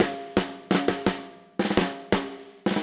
Index of /musicradar/80s-heat-samples/85bpm
AM_MiliSnareA_85-02.wav